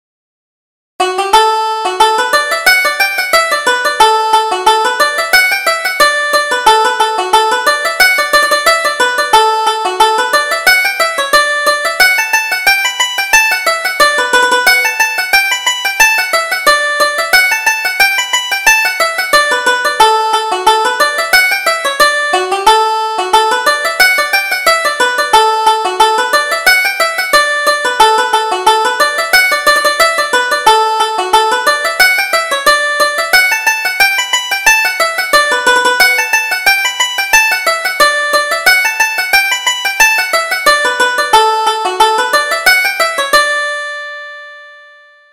Reel: Molly What Ails You? (1907 Version)